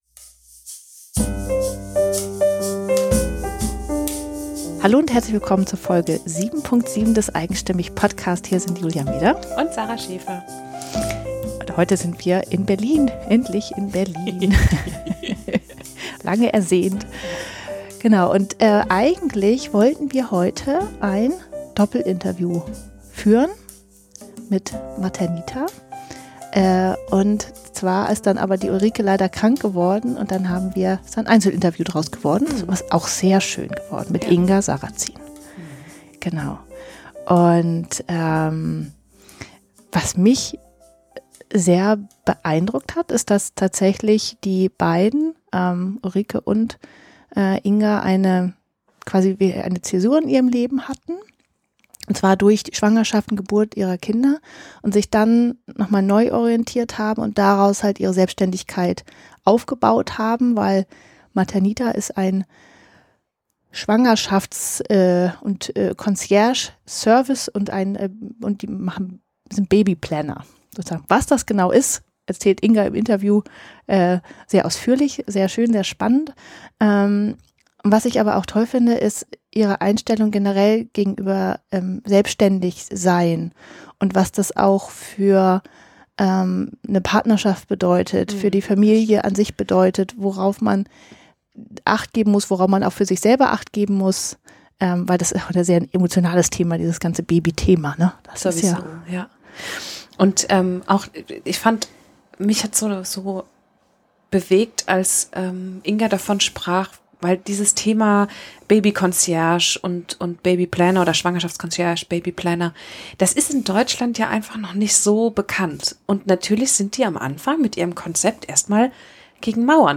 Am wichtigsten ist es ihr, Frauen während der Schwangerschaft und junge Eltern nicht zu bewerten. 47 Minuten 37.95 MB Podcast Podcaster eigenstimmig Interviews mit einzigartigen Frauen*, die lieben, was sie tun.